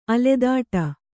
♪ aledāṭa